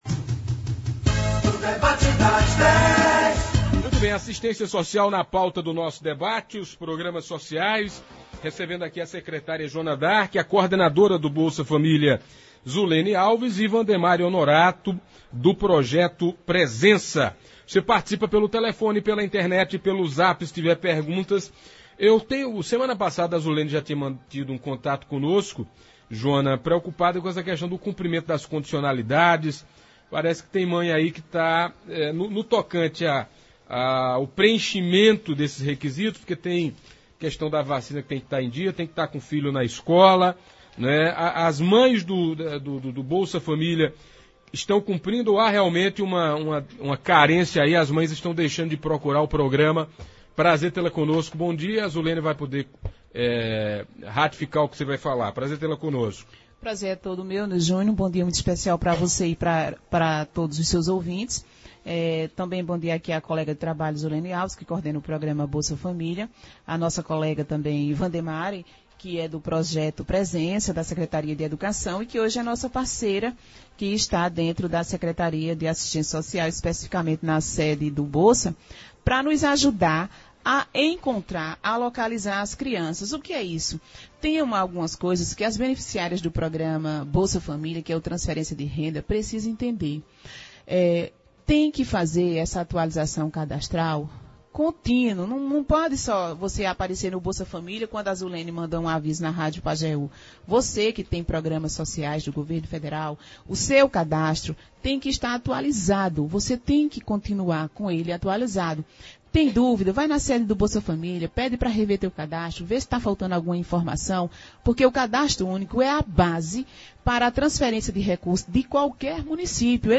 Os ouvintes e internautas puderam tirar dúvidas e fazer questionamentos às convidadas.